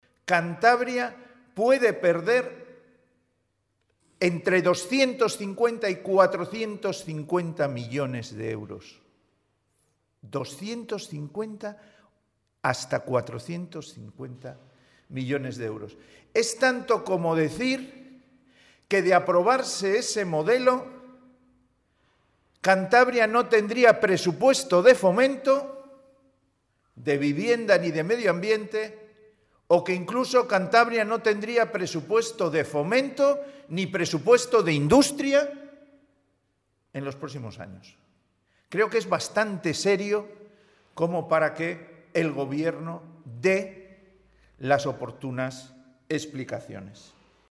Pedro Hernando en la rueda de prensa que ha ofrecido hoy
Ver declaraciones de Pedro Hernando, portavoz del Partido Regionalista de Cantabria.